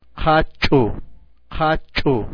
Tabla I: Alfabeto Oficial sonorizado
Oclusivas aspiradas ph